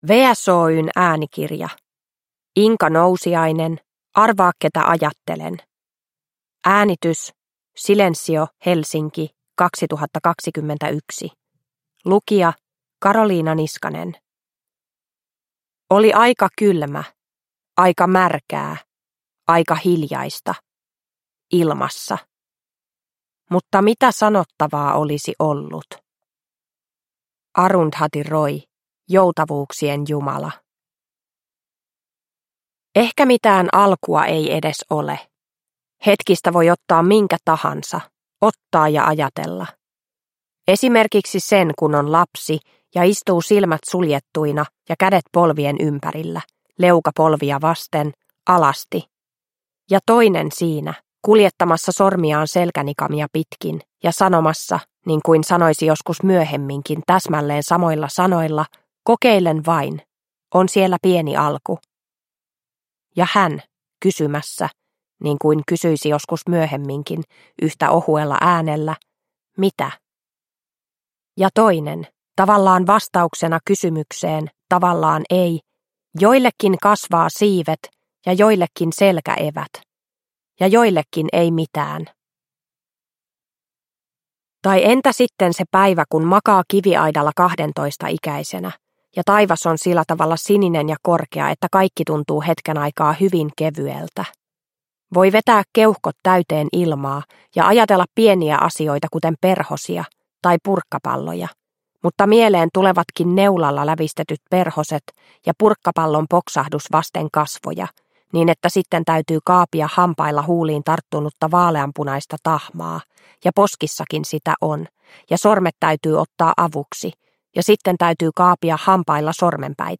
Arvaa ketä ajattelen – Ljudbok – Laddas ner